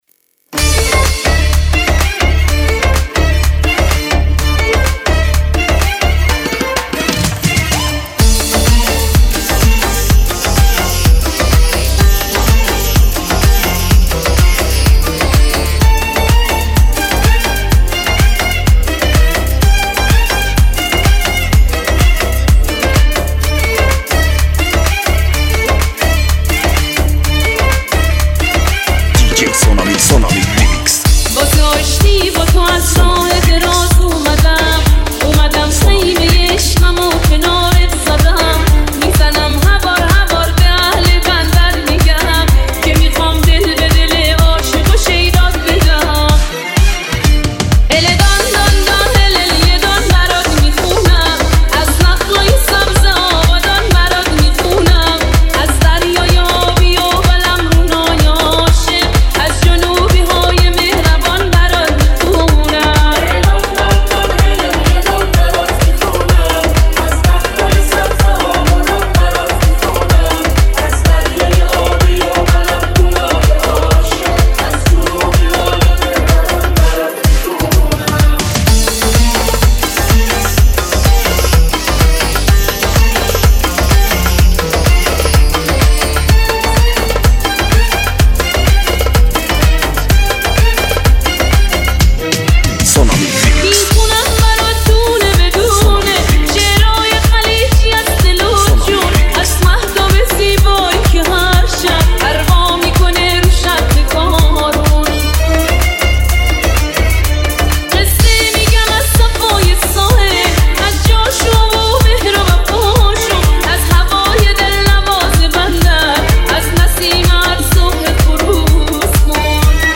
با صدای زن
ریمیکس صدای دختر